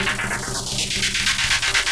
RI_ArpegiFex_125-01.wav